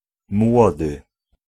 Ääntäminen
US : IPA : /smɔl/ UK : IPA : /smɔːl/